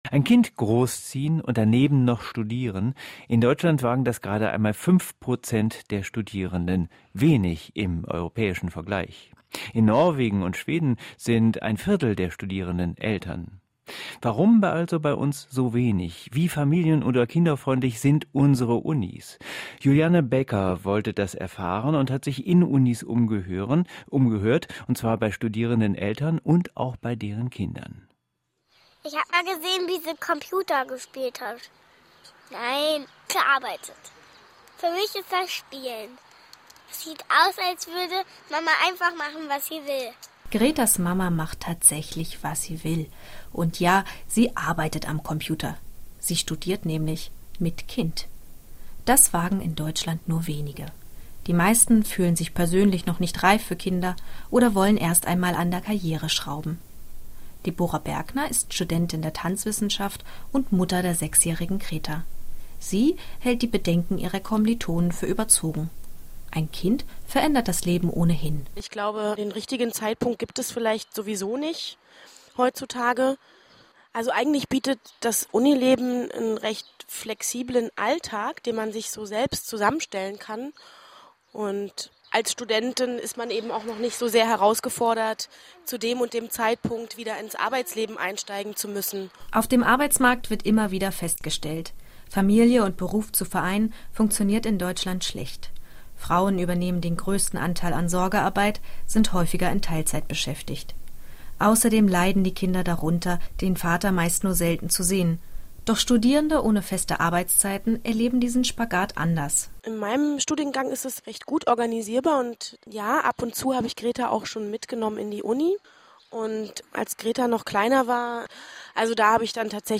Radiospot zu "Studieren mit Kind"
Ein Beitrag des Dual Career & Family Service im kulturradio rbb
radiospot-studieren-mit-kind.mp3